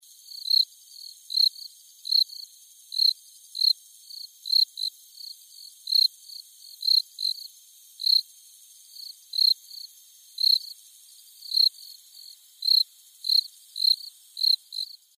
Звуки стрекотания
2. Трели сверчков